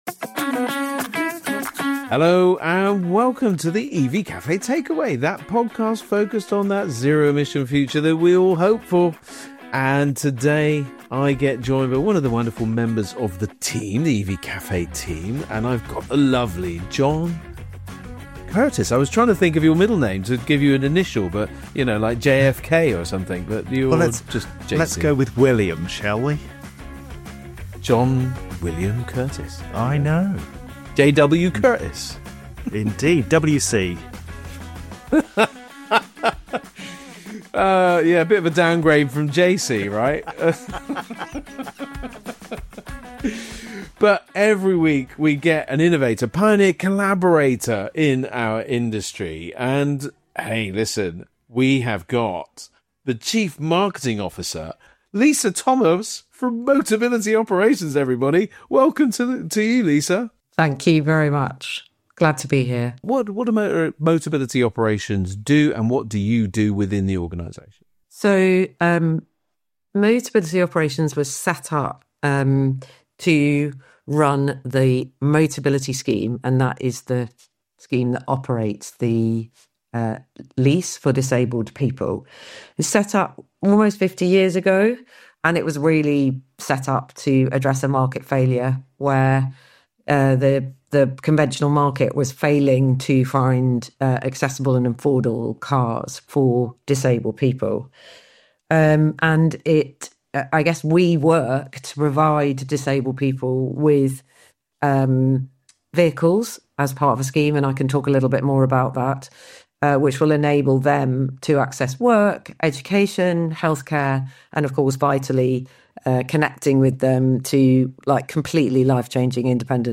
From emotional customer stories to future‑facing EV strategy, this conversation shines a light on one of the UK’s most impactful and forward‑thinking organisations.